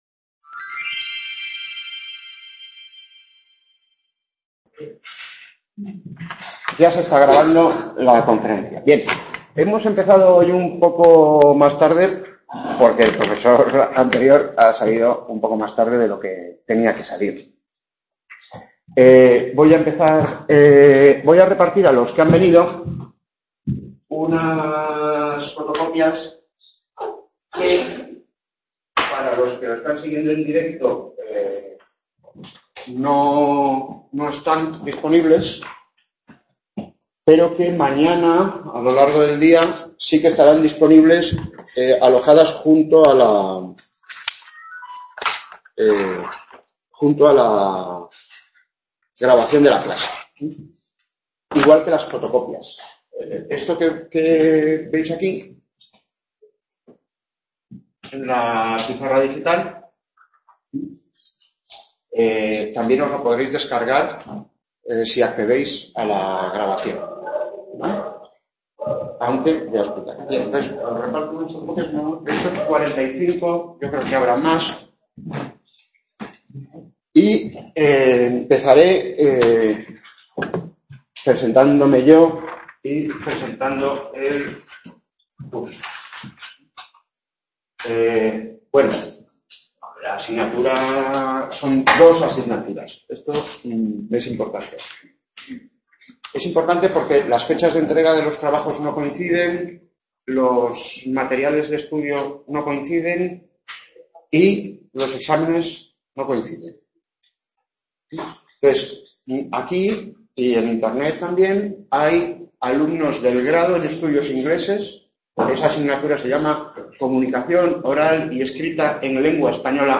Tutorías de las asignaturas Comunicación oral escrita en lengua española I (Grado en estudios ingleses) y Comunicación oral y escrita en español I (Grado en lengua y literatura españolas)